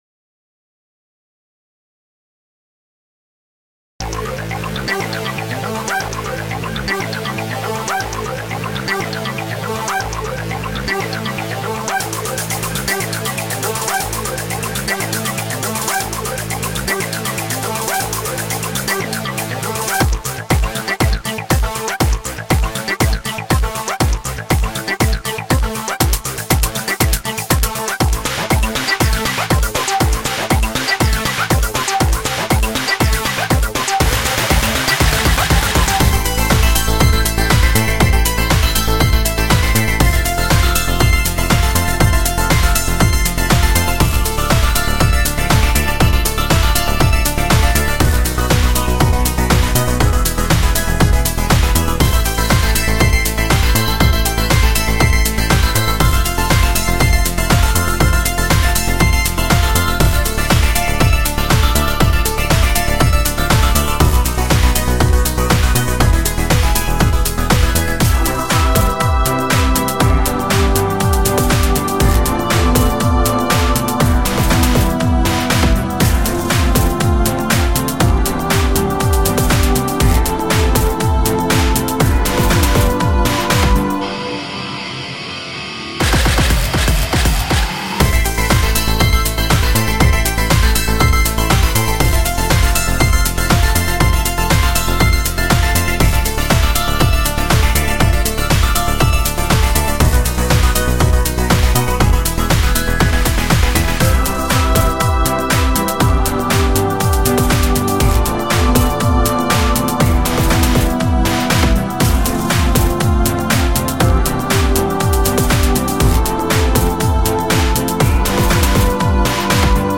フリーBGM 戦闘曲